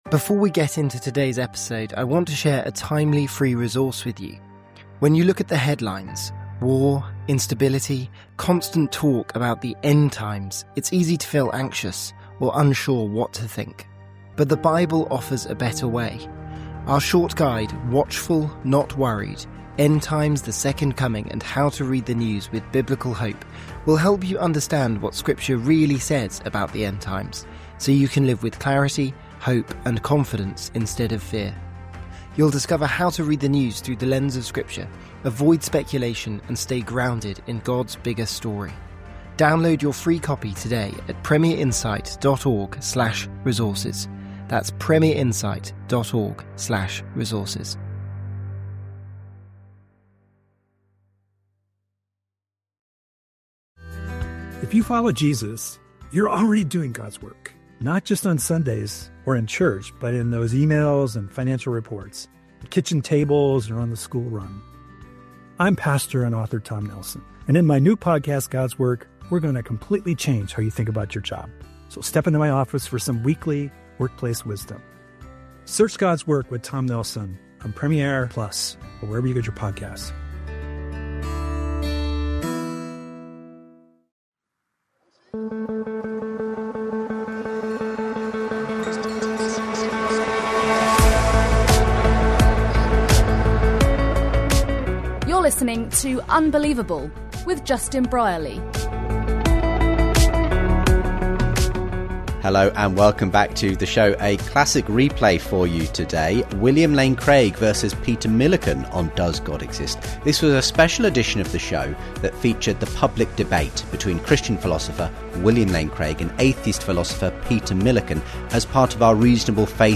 This special edition of the show featured the public debate between Christian Philosopher William Lane Craig and atheist Philosopher Peter Millican as part of the Reasonable Faith Tour 2011.
They debated "Does God Exist?" before a capacity crowd at Bill's Alma Mater, Birmingham University where he studied for his first Doctorate.